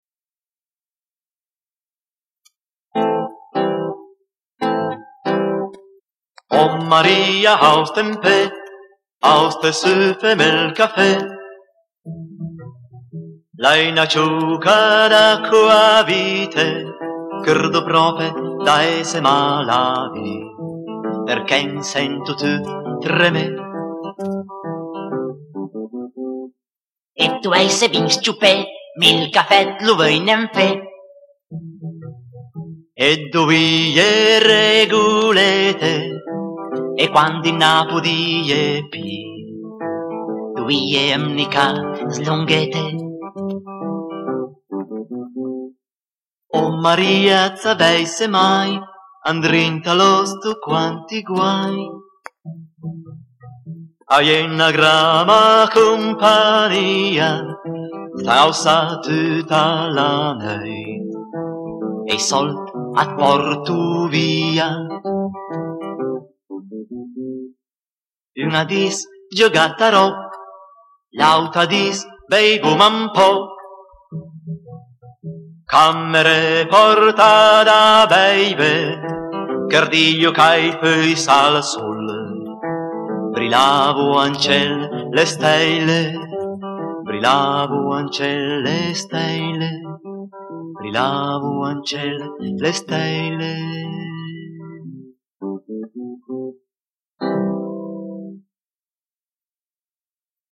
Canzone popolare